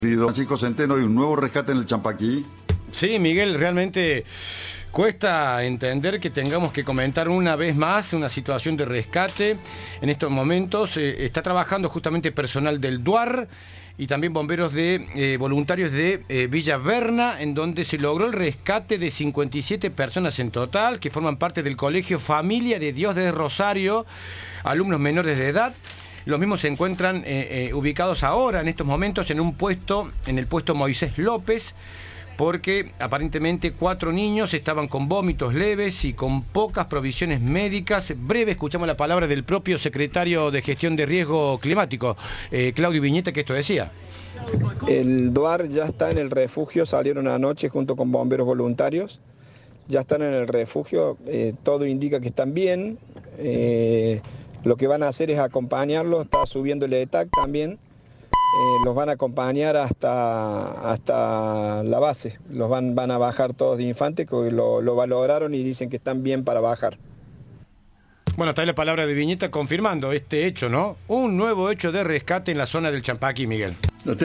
En diálogo con Cadena 3
Informe